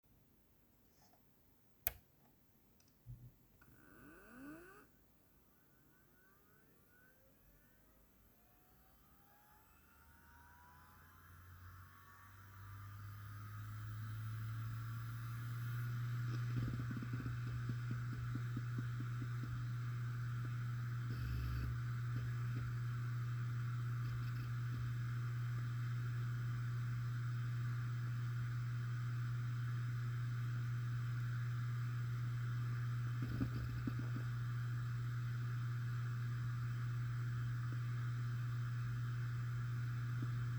sollten ein paar jahre später durch eine dritte aus der gleichen reihe erweitert werden - nur dass wd jetzt bei der kein helium mehr eingesetzt hat und das ding sich anhört, als wenn ständig wasser durch die heizung läuft + vibrationen und zugriffsgeräusche.